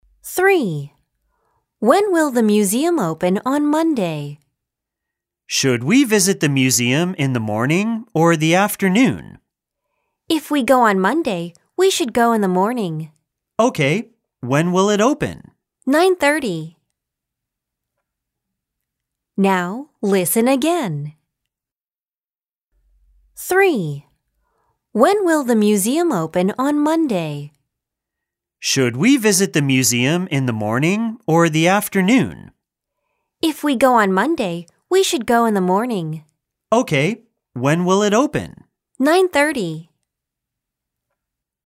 A2 聽力測驗錄音檔 (可直接播放或下載)